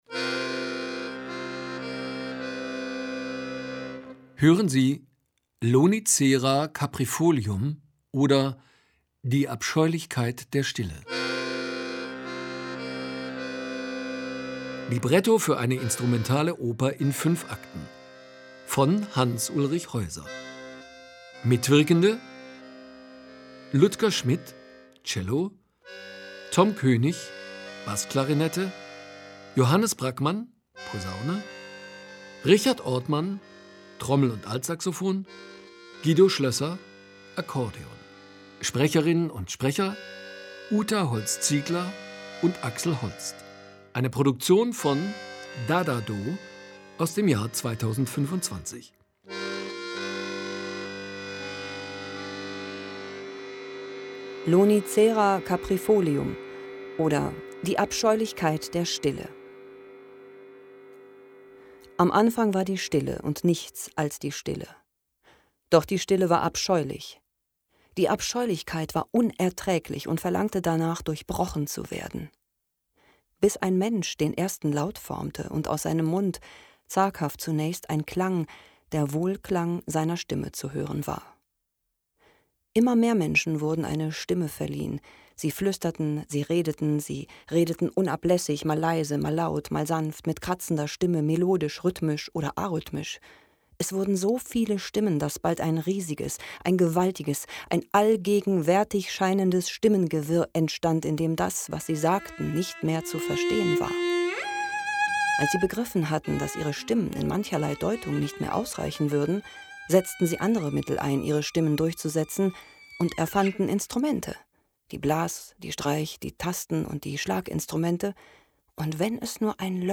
Lonicera caprifolium – eine instrumentale Oper in fünf Akten*
Lonicera caprifolium, eine Oper ohne Gesang, ist ein absurdes, ein dadaistisches Hörstück.
Posaune
Bassklarinette Akkordeon
Violoncello
Sax und Trommel
Sprecher und Sprecherin